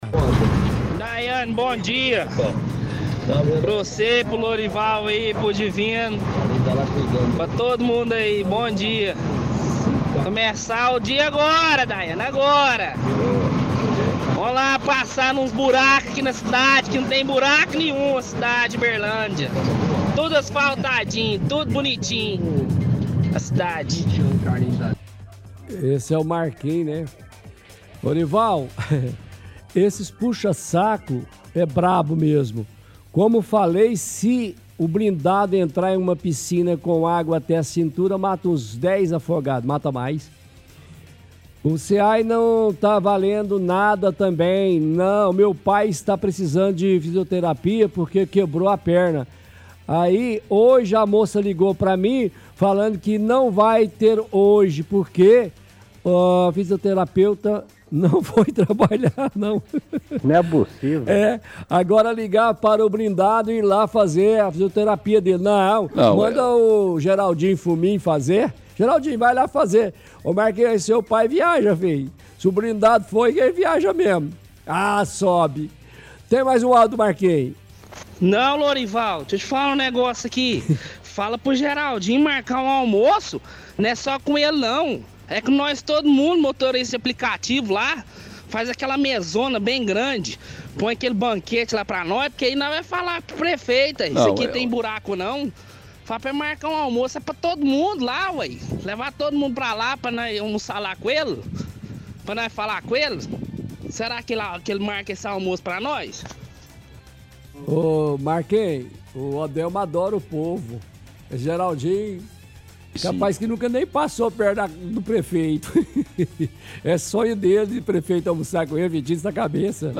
– Retransmissão de áudio de ouvinte defendendo o prefeito.
– Outro ouvinte envia áudio ironizando que a cidade não tem buraco.